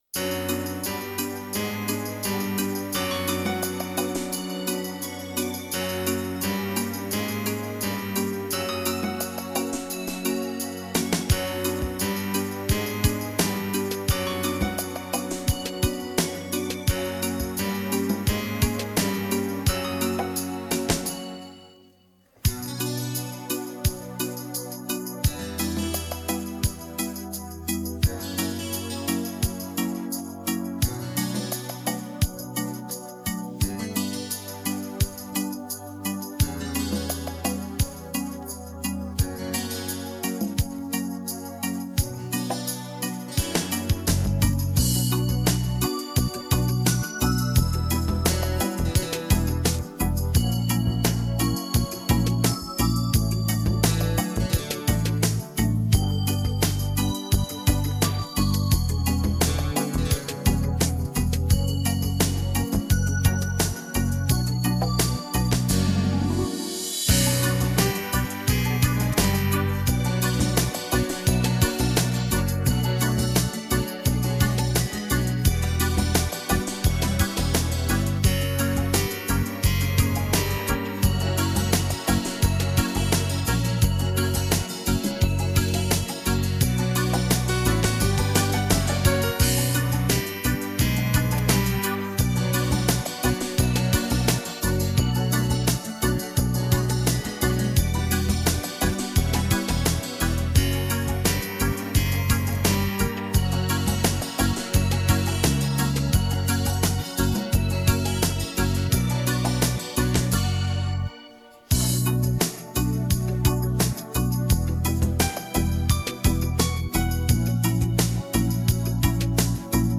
(минус)